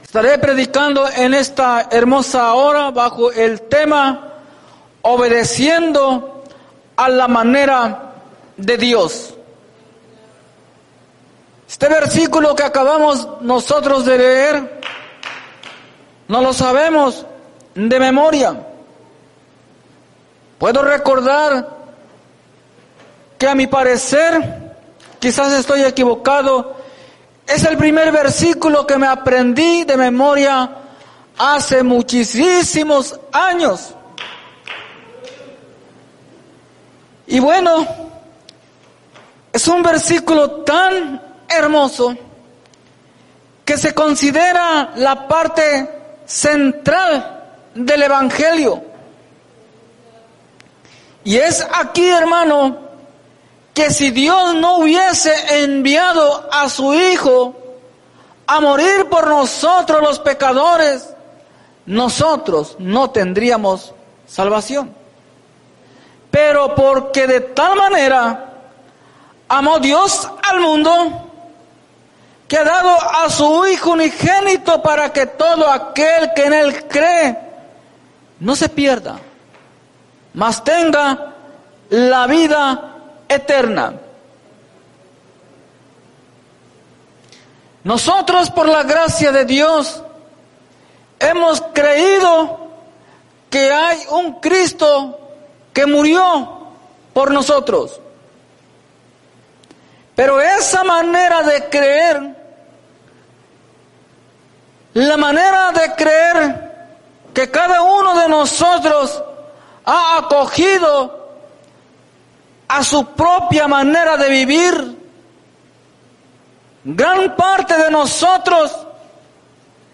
Obedeciendo a la manera de Dios Predica